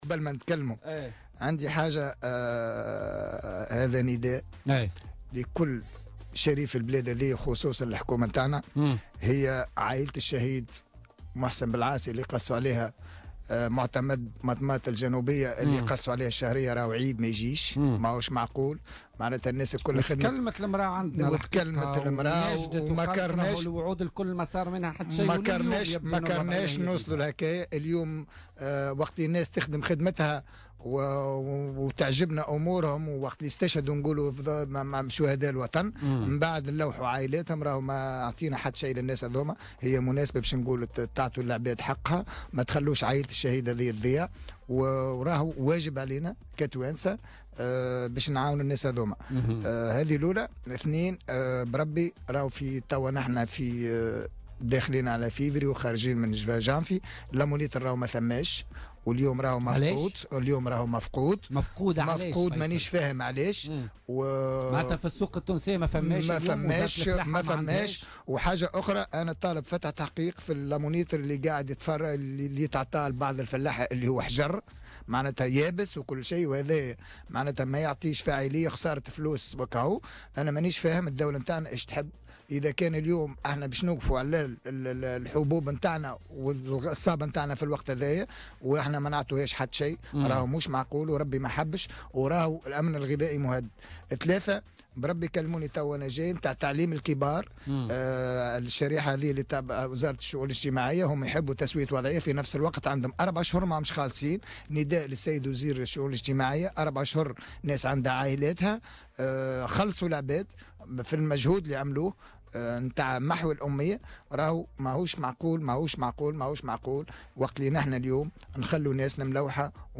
أكد النائب عن حزب صوت الفلاحين فيصل التبيني ضيف بولتيكا اليوم الثلاثاء 30 جانفي 2018 ضرورة تسليح الفلاحين ومنحهم تراخيص مسك سلاح في ظل تفاقم ظاهرة سرقة المحاصيل والمواشي .